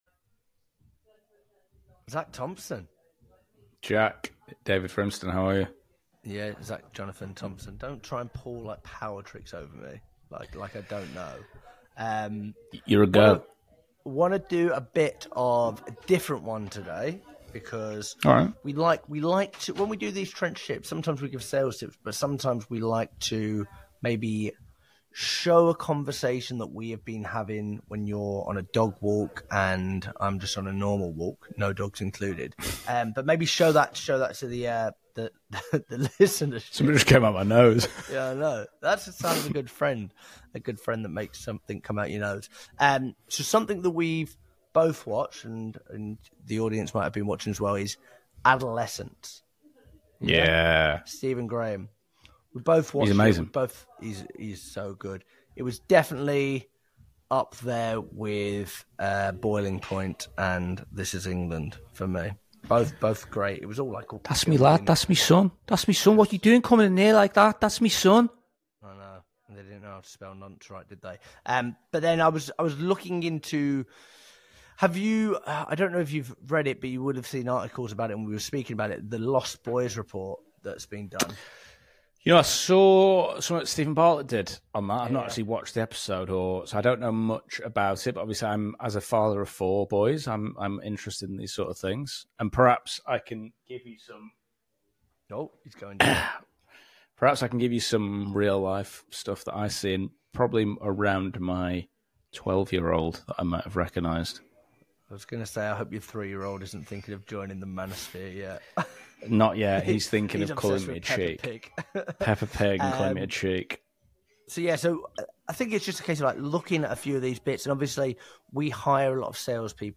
Just two men trying to make sense of it all.